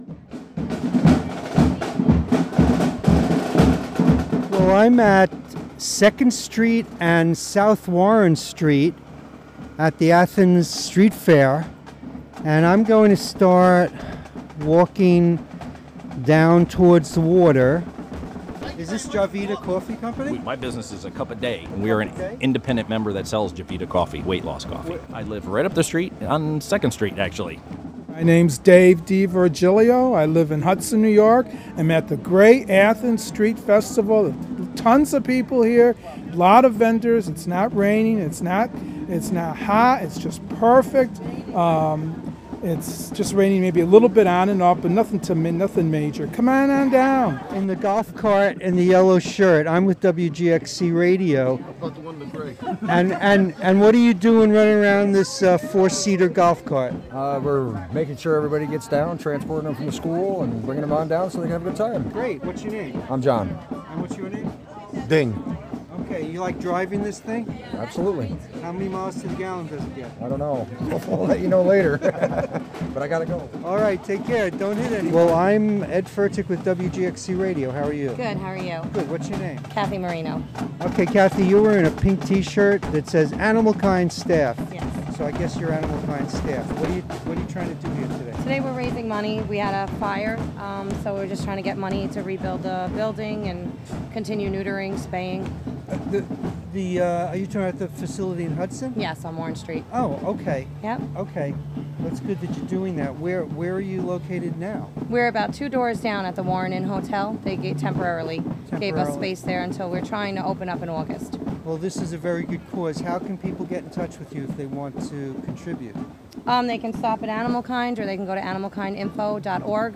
Sound collage from Athens Street Festival 2013.
AthensStreetFest2013.mp3